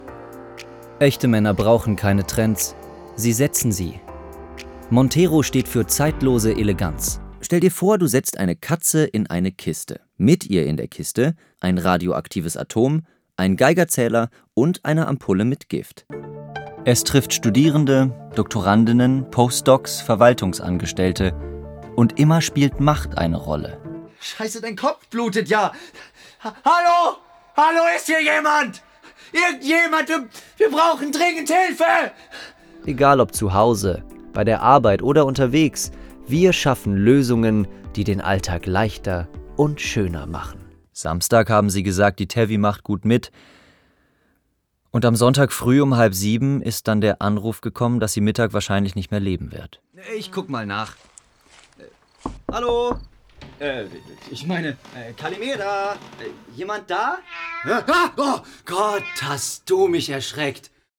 Mein Showreel